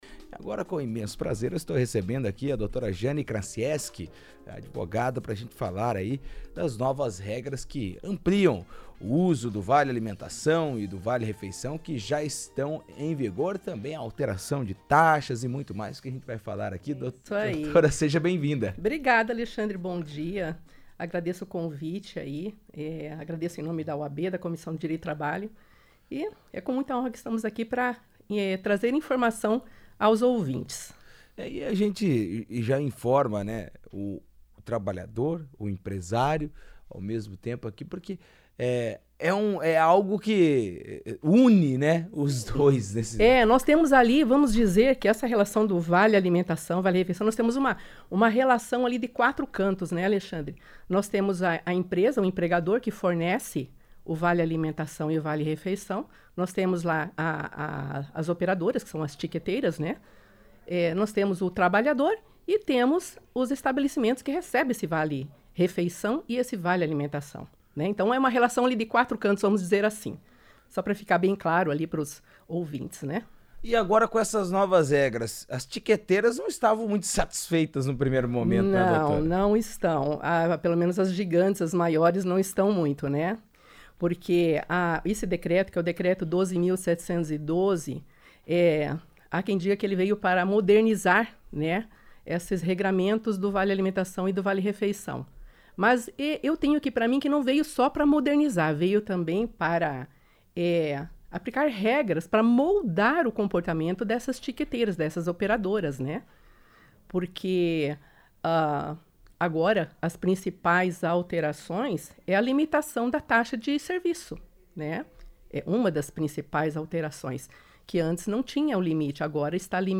Em entrevista à CBN